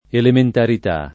elementarità
vai all'elenco alfabetico delle voci ingrandisci il carattere 100% rimpicciolisci il carattere stampa invia tramite posta elettronica codividi su Facebook elementarità [ elementarit #+ ] s. f. — non elementarietà